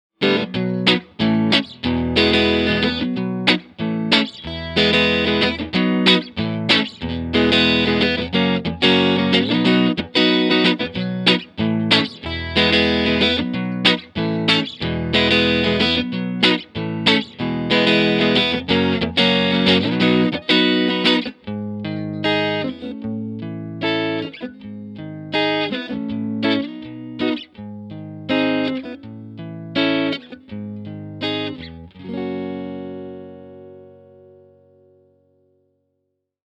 Mic was a single SM57, to Vintech (Neve-style) pre, to Apogee Rosetta 200 A/D, to the computer.
Ch.1 Clean was as follows - no MV, cut 3:00, vol 7:30, contour pos 2 (from left), munch/hi on the back, Lo input on the front.
TC15_Ch1_Clean_Tele_Neck.mp3